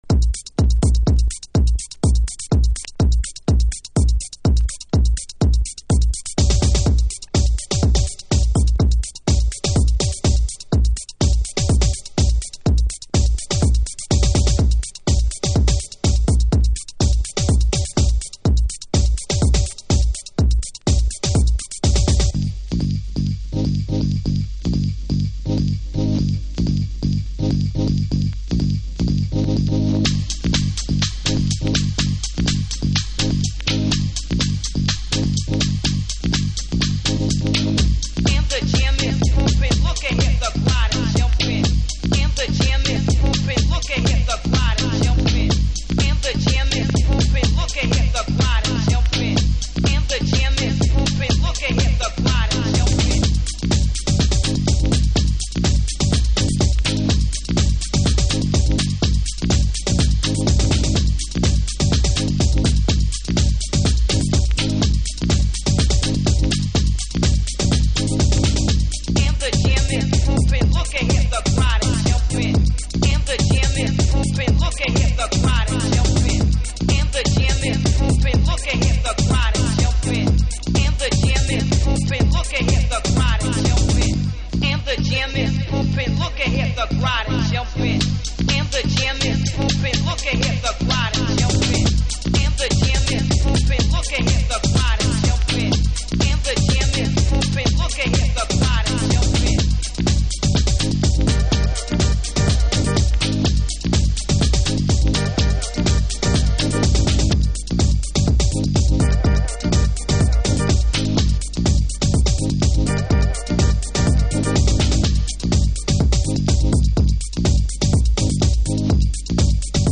Early House / 90's Techno
この再発シリーズのありがたいトコロは原曲を崩さずに音を太くしてくれています。